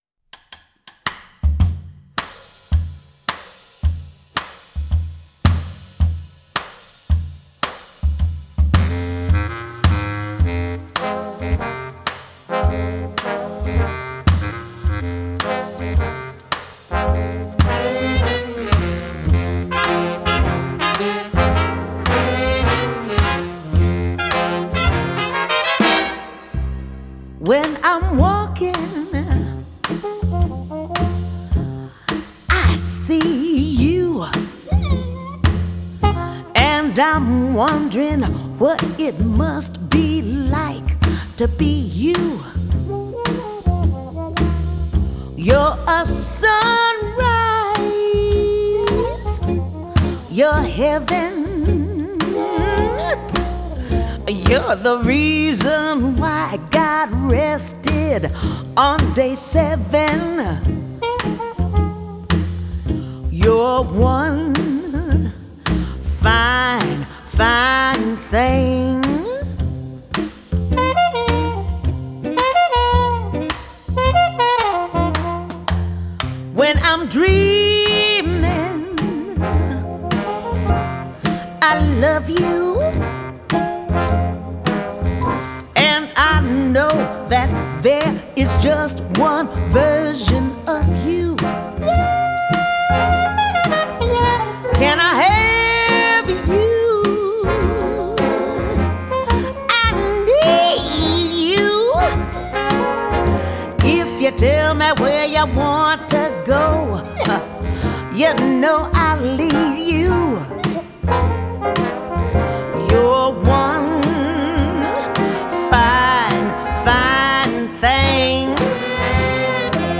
Жанр: Jazz.